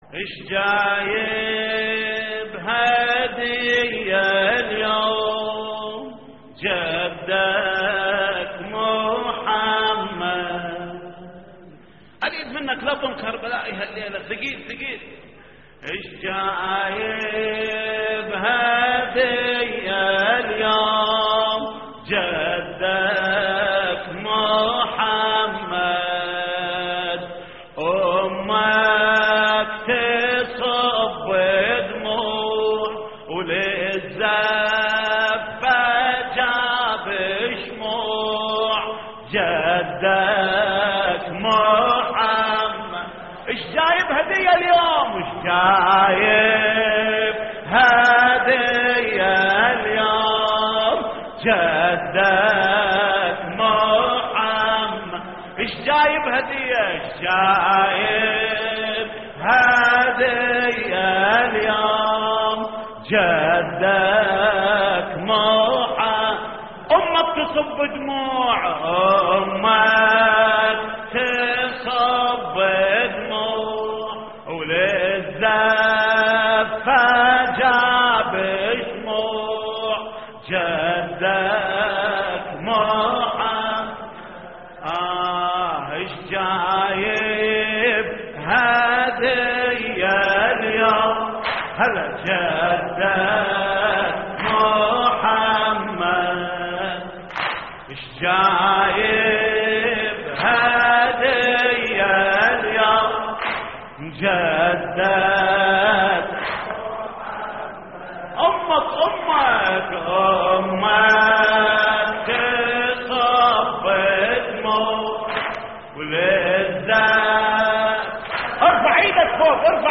تحميل : اشجايب هدية اليوم .. جدك محمد / الرادود جليل الكربلائي / اللطميات الحسينية / موقع يا حسين